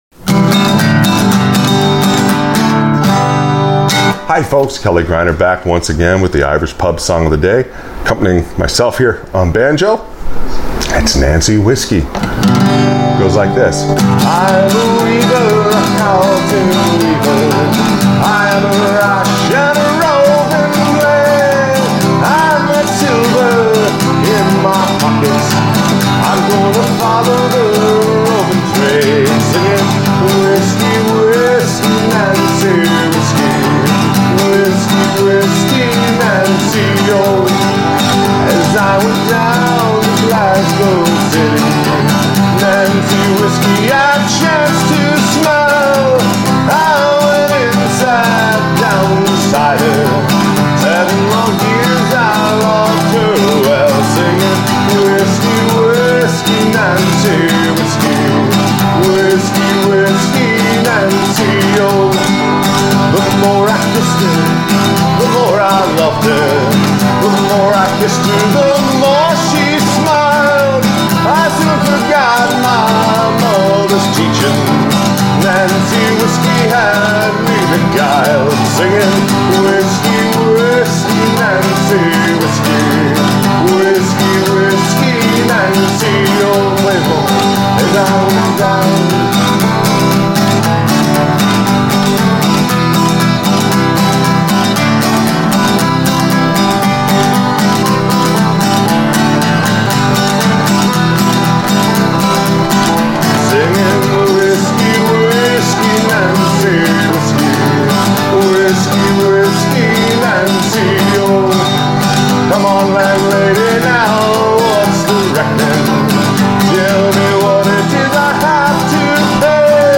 Irish Pub Song Of The Day – Nancy Whiskey Accompaniment for Frailing Banjo